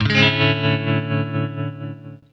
06 Revealed F#min.wav